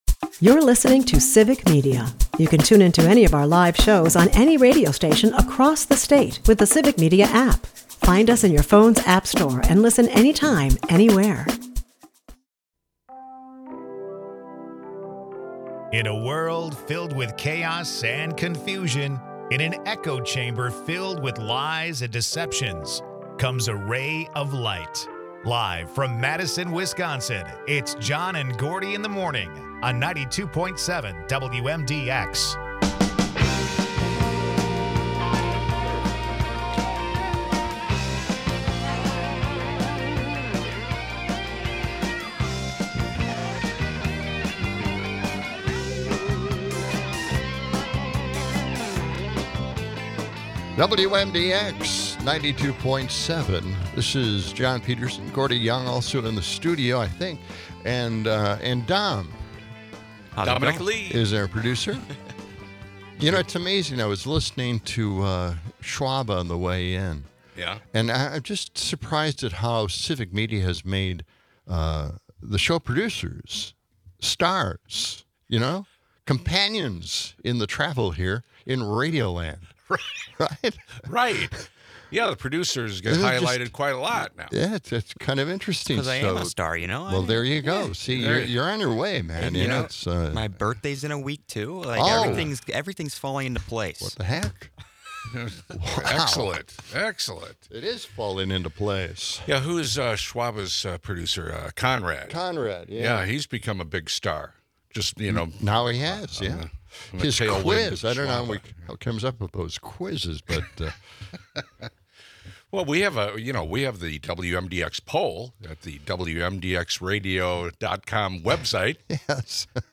All this with a splash of weather updates and Verlo Mattress promotions!